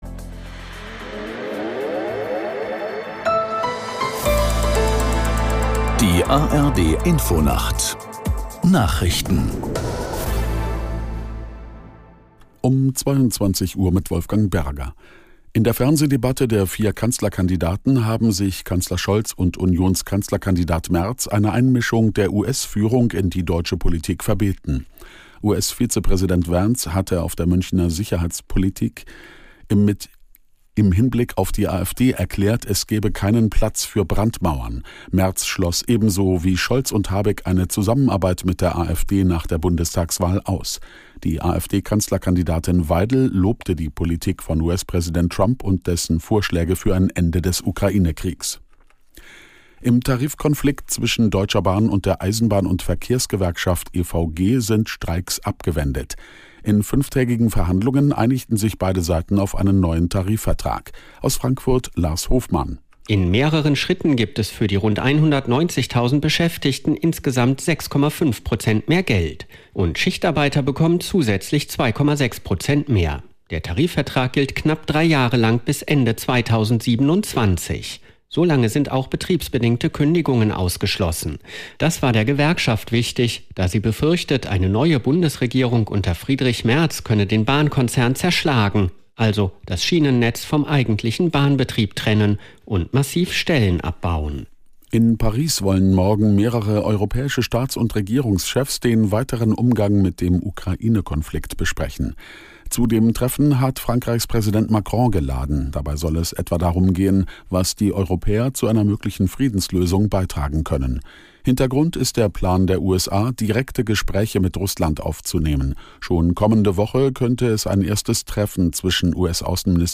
Nachrichten - 16.02.2025